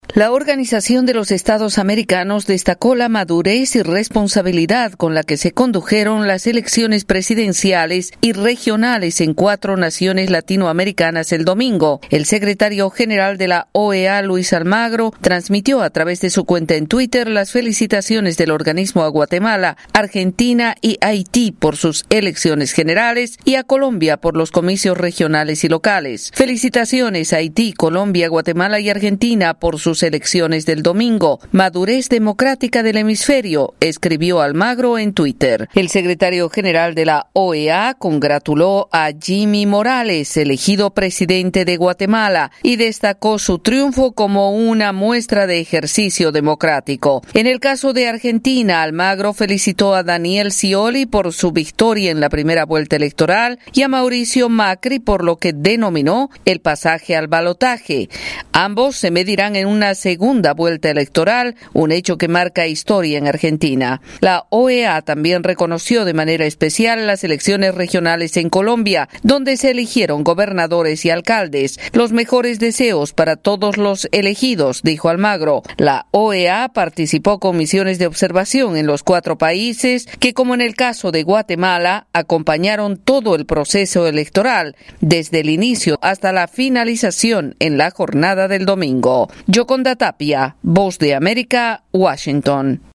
La Organización de los Estados Americanos expresó su felicitación a las naciones latinoamericanas que tuvieron elecciones el domingo. Desde la Voz de América en Washington DC